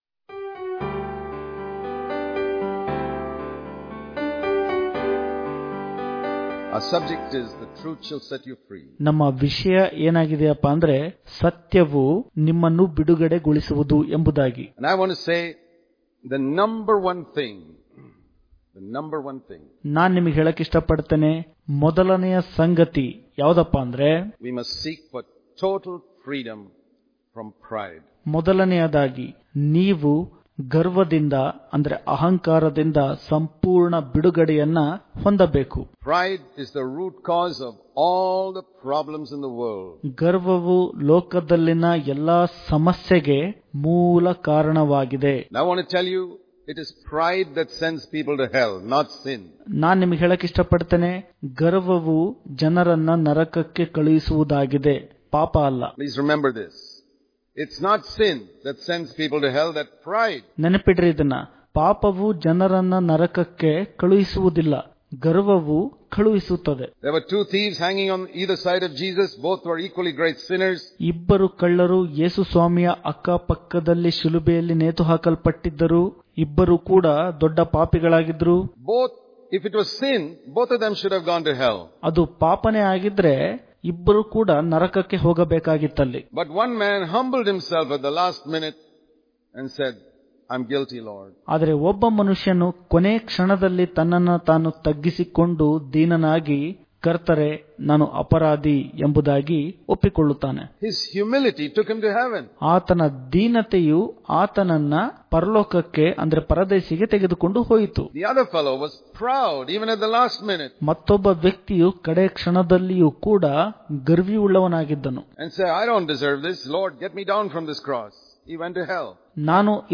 September 26 | Kannada Daily Devotion | Freedom From The Cage Of Pride Daily Devotions